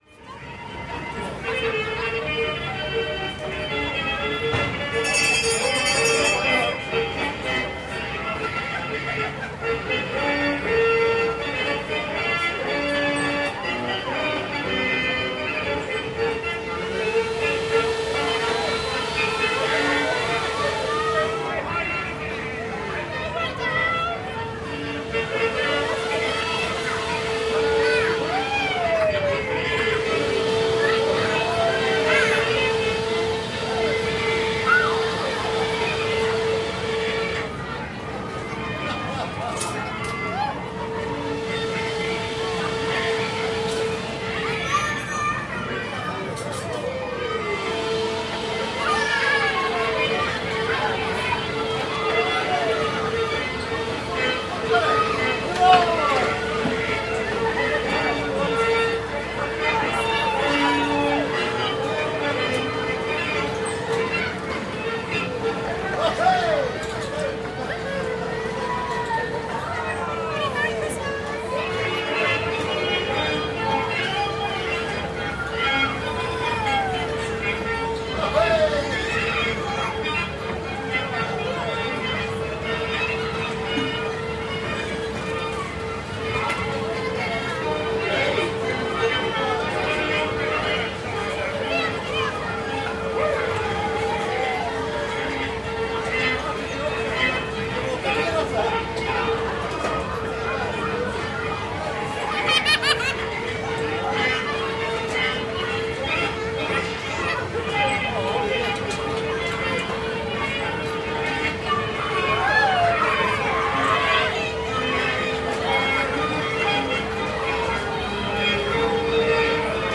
Звуки карусели: Атмосферные нотки детского смеха и веселья